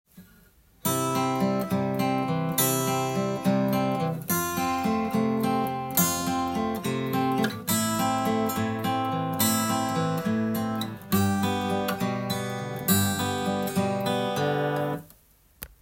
【ピック＆指弾きハイブリット練習２】
③も同様に１拍目にベース音と指弾きが入りますが
２拍目と４拍目にも指弾きが入ってきています。